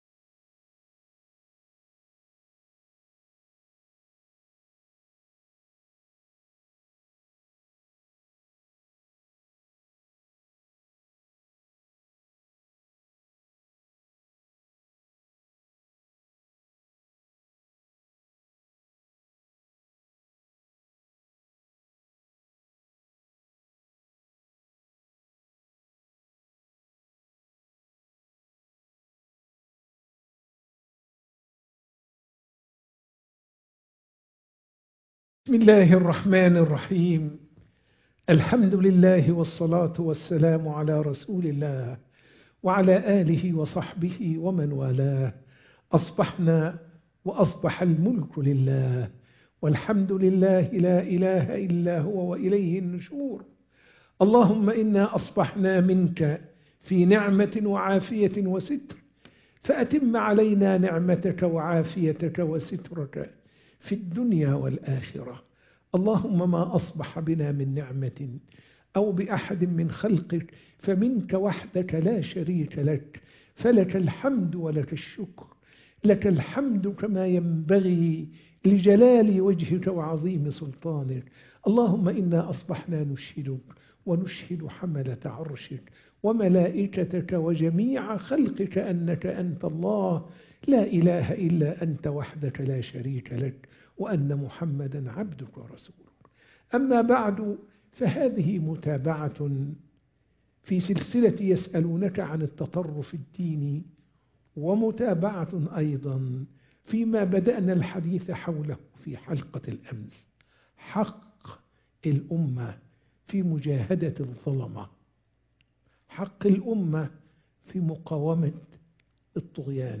درس الفجر - يسألونك عن التطرف الديني